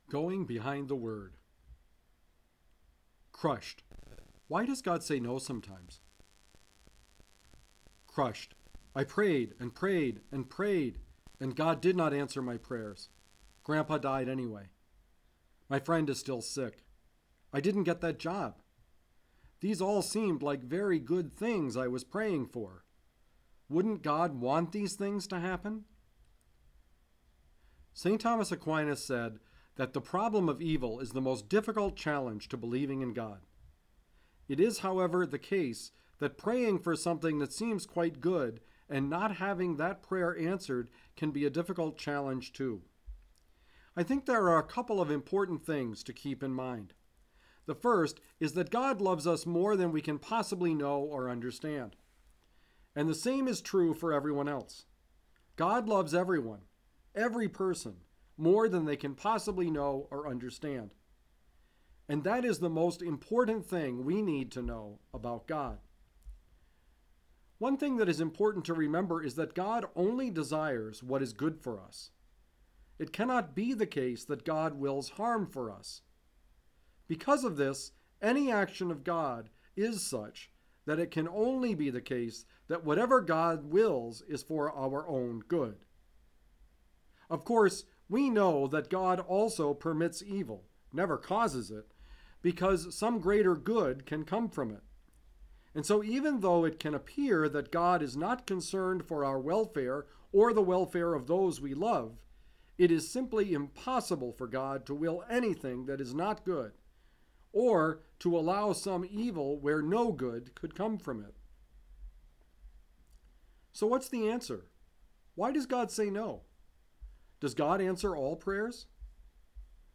Going Behind the Word Commentary